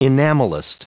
Prononciation du mot enamelist en anglais (fichier audio)
Prononciation du mot : enamelist